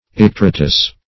Search Result for " icteritous" : The Collaborative International Dictionary of English v.0.48: Icteritious \Ic`ter*i"tious\, Icteritous \Ic*ter"i*tous\, a. Yellow; of the color of the skin when it is affected by the jaundice.